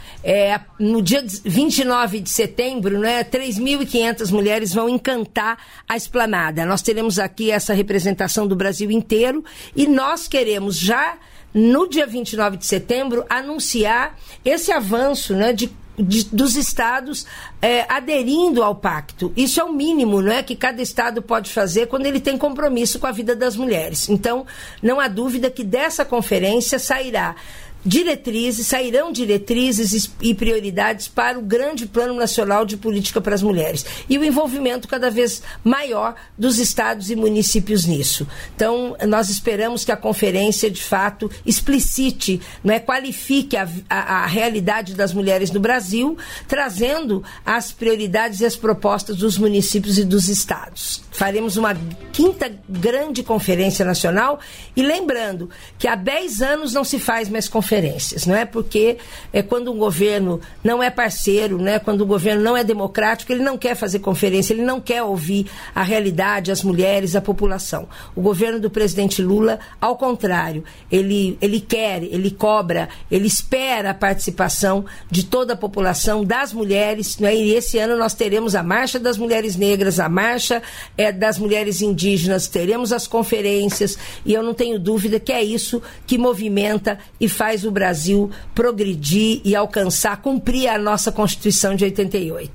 Trecho da participação da ministra das Mulheres, Márcia Lopes, no programa "Bom Dia, Ministro" desta quinta-feira (31), nos estúdios da EBC em Brasília (DF).